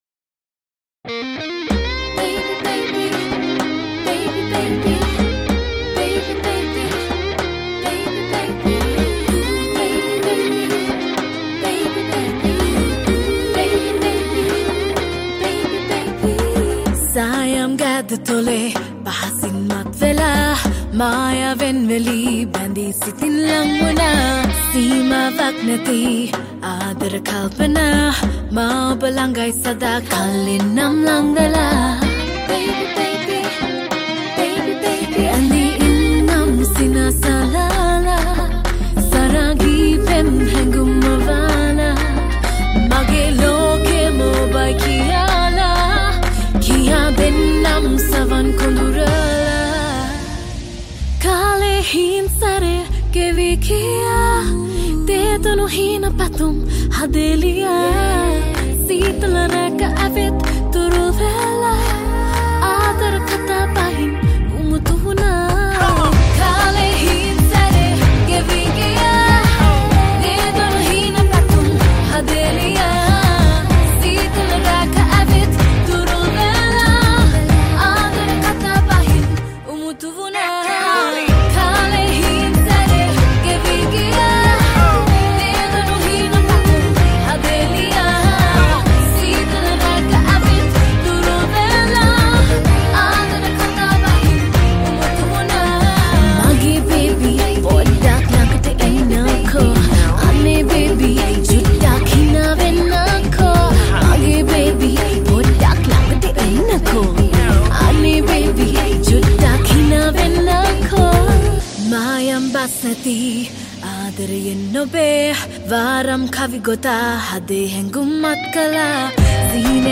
Vocals
Guitars